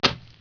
1 channel
splats1p.wav